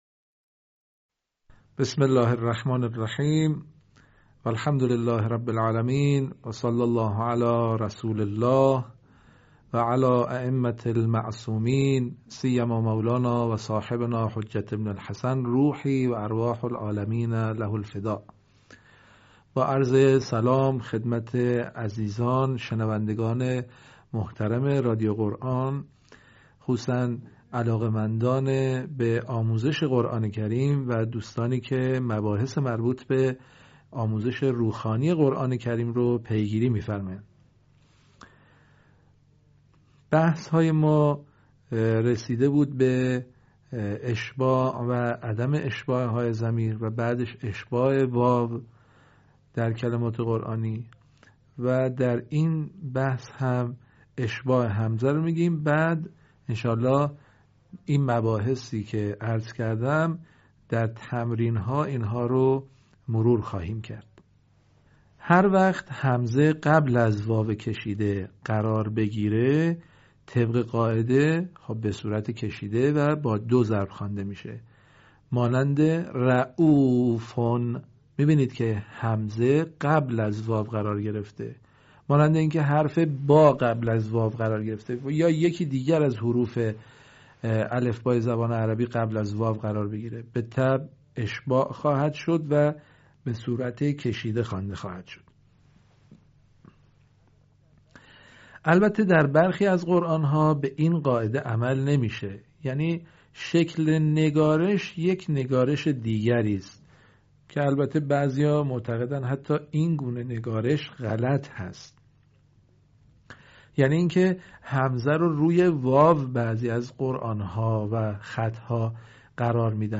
صوت | آموزش «همزه» در روخوانی و روانخوانی قرآن کریم